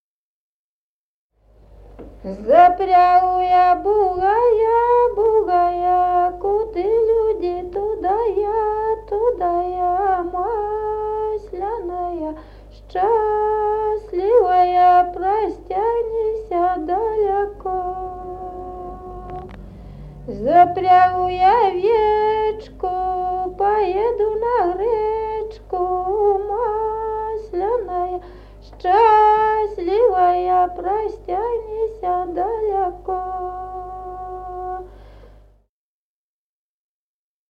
Народные песни Стародубского района «Запрягу я бугая», масленичная.
1953 г., с. Мохоновка.